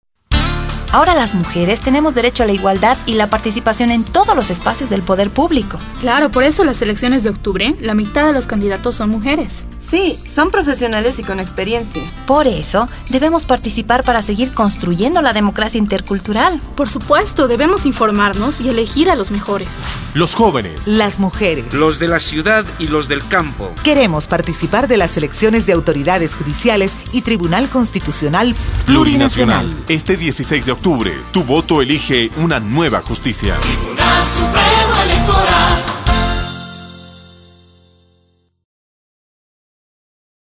Cuñas